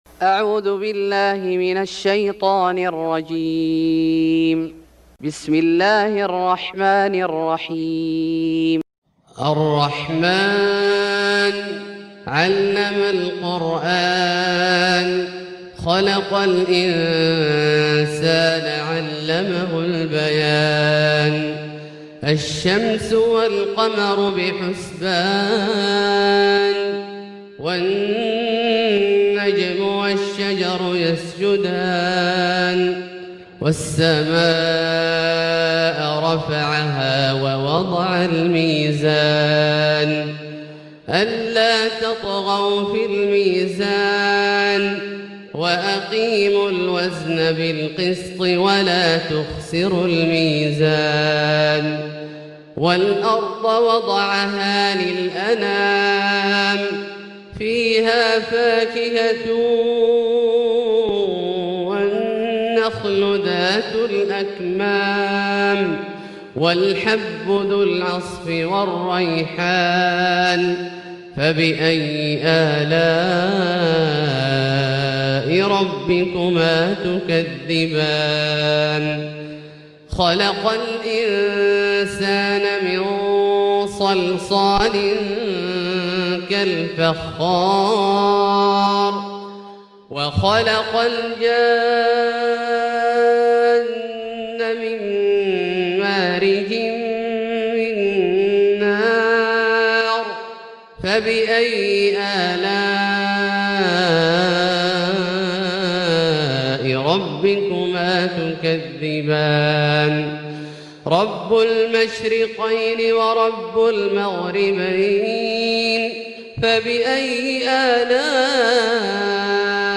سورة الرحمن Surat Ar-Rahman > مصحف الشيخ عبدالله الجهني من الحرم المكي > المصحف - تلاوات الحرمين